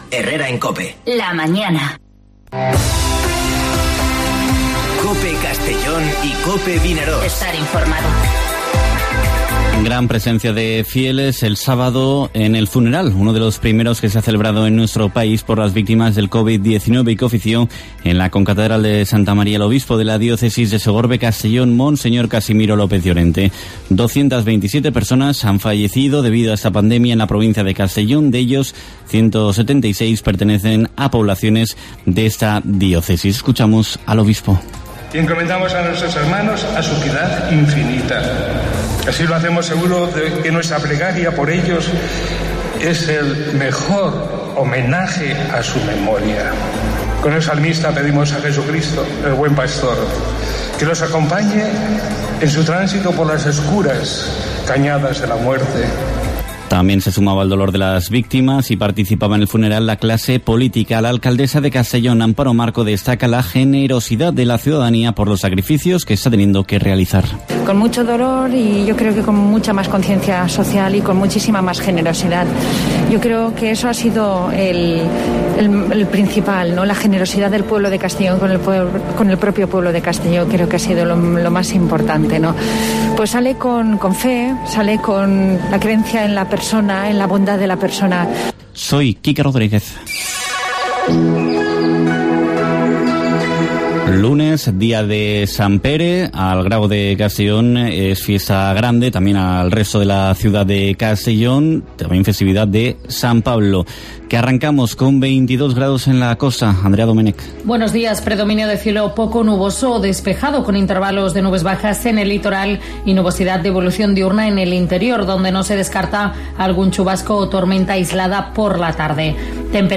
Informativo Herrera en COPE en la provincia de Castellón (29/06/2020)